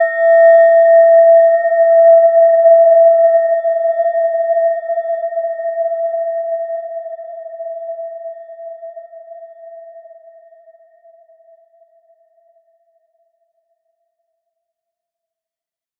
Gentle-Metallic-4-E5-mf.wav